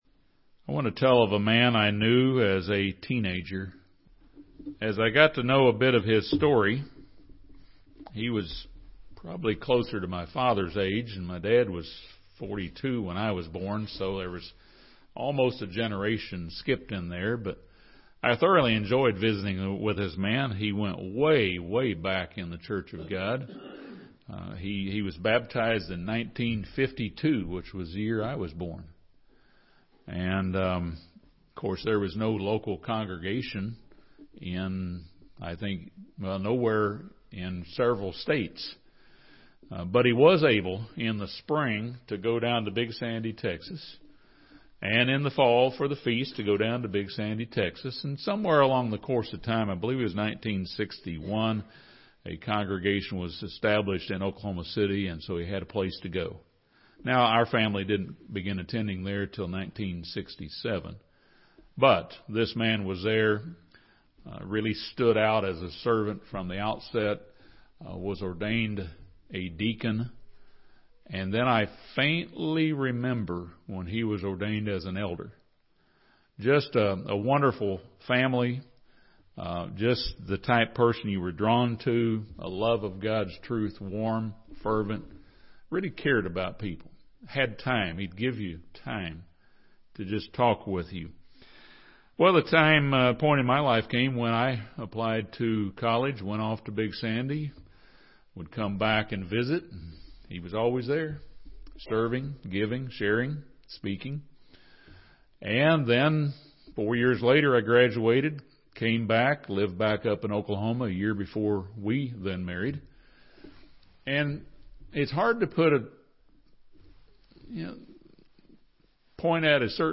This sermon discusses the importance of repentance, teamwork, respect, sacrifice, dedication, and staying close to God as we train to reign with Christ in His Kingdom.